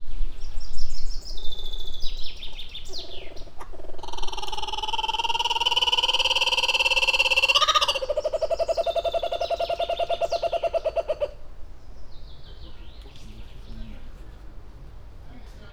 Directory Listing of /_MP3/allathangok/veszpremizoo2013_premium/kookaburra_kacagojancsi/